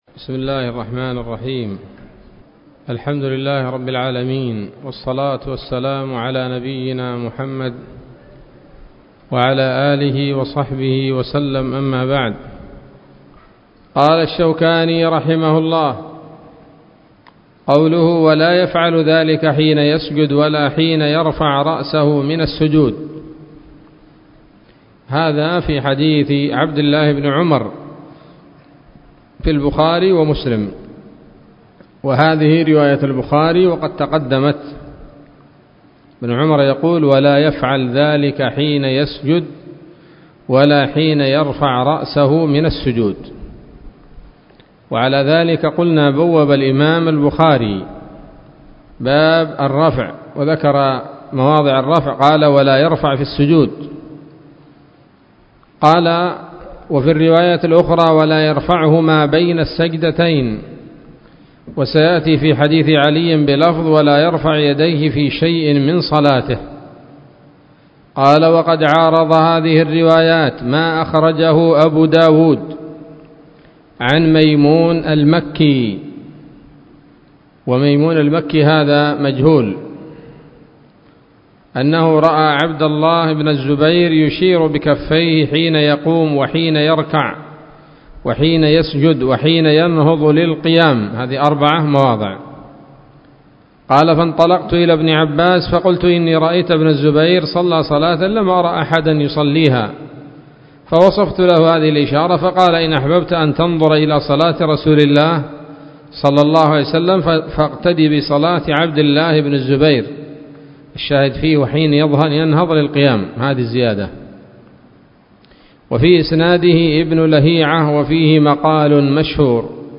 الدرس الثامن من أبواب صفة الصلاة من نيل الأوطار